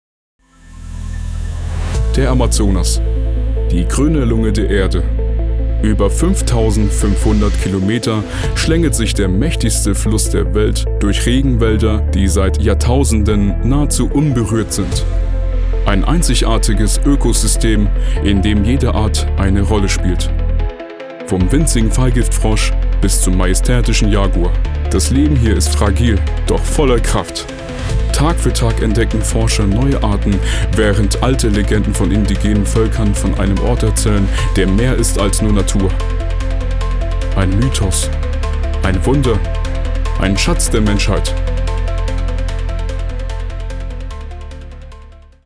Tiefe Stimme
Sprechprobe: eLearning (Muttersprache):